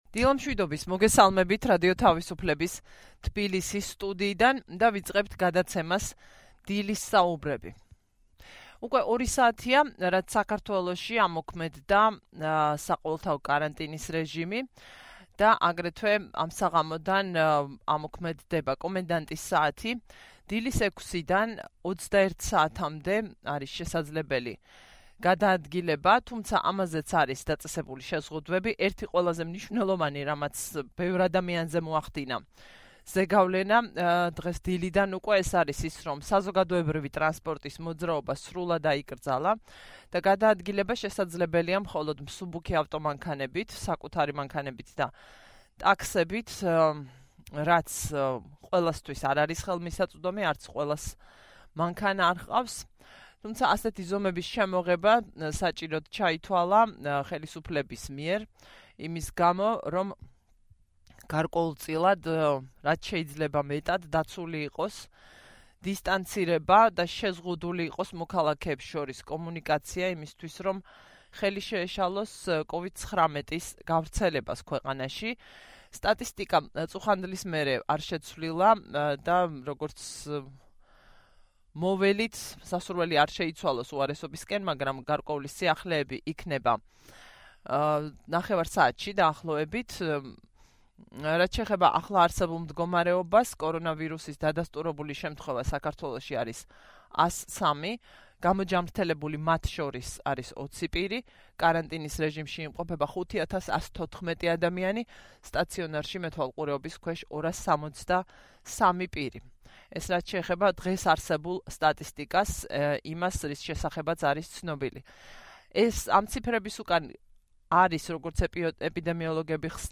ეს იმედი მან ინტერვიუს დასასრულს გამოთქვა.